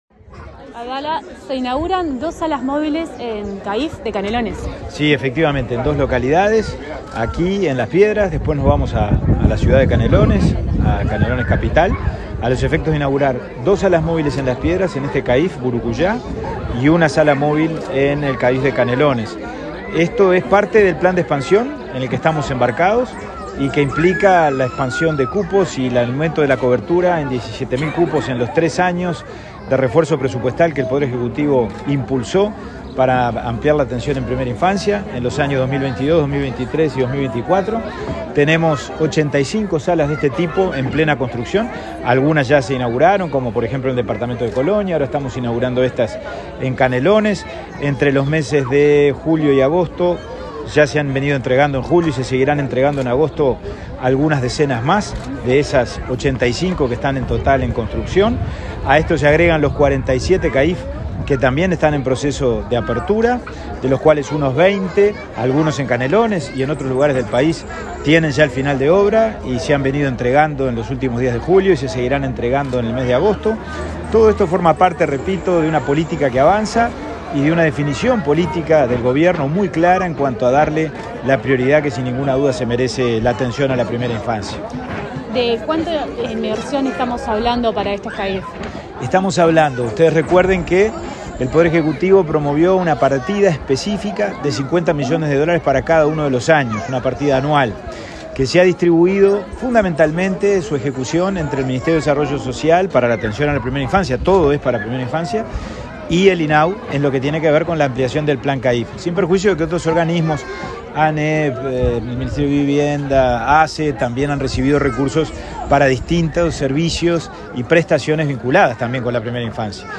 Entrevista al presidente del INAU, Pablo Abdala
Este martes 1.°, el presidente del Instituto del Niño y el Adolescente del Uruguay (INAU), Pablo Abdala, dialogó con Comunicación Presidencial acerca